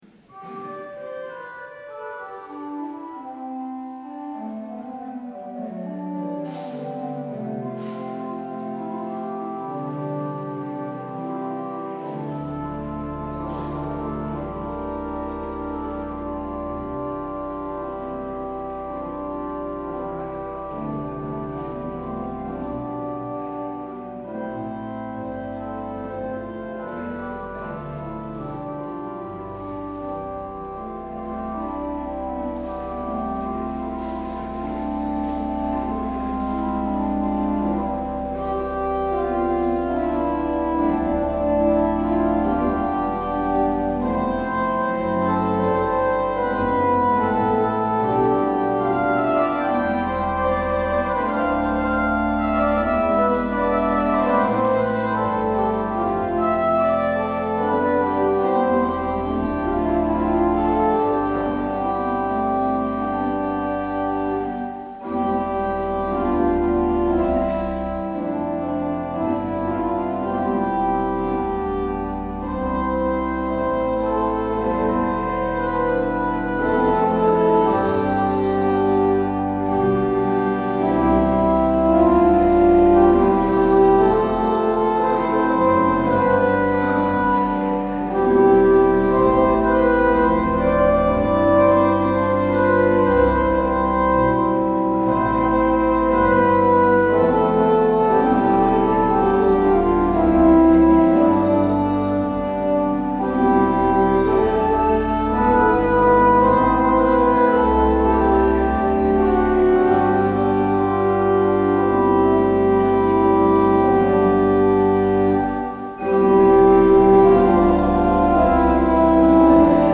 Luister deze kerkdienst hier terug: Alle-Dag-Kerk 29 november 2022 Alle-Dag-Kerk https